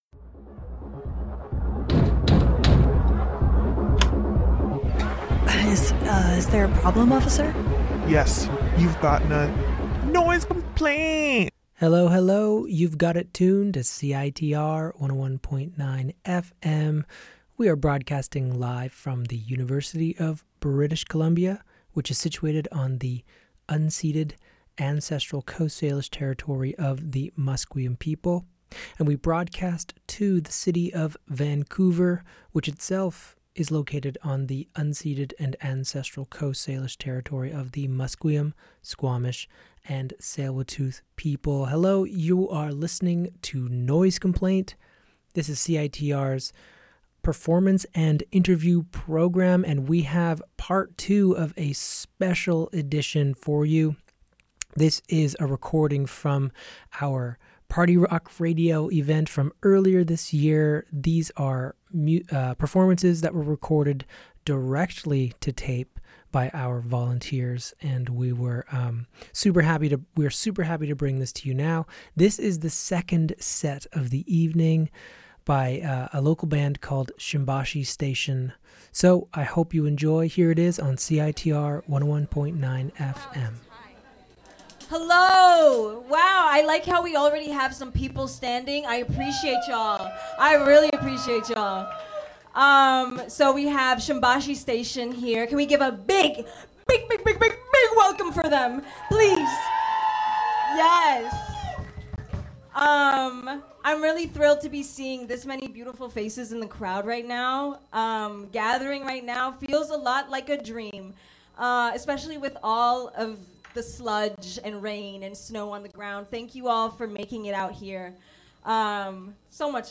A live session recorded directly to tape!